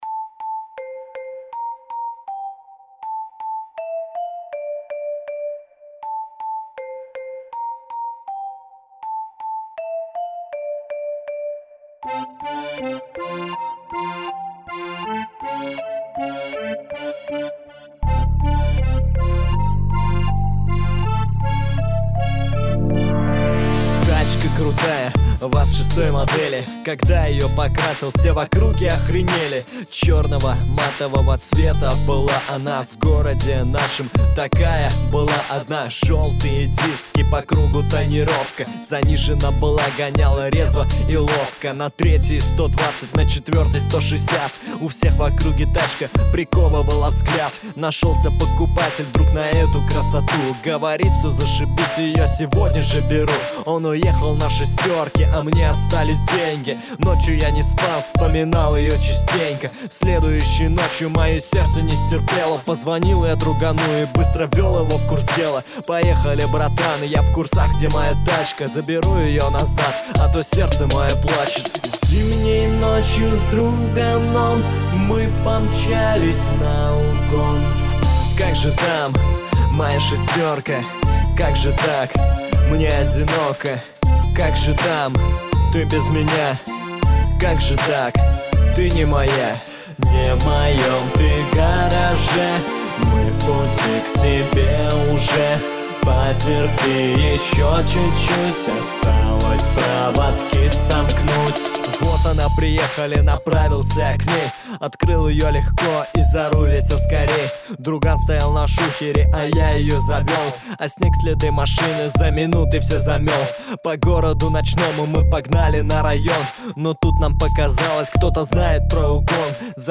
Сэмплы: свои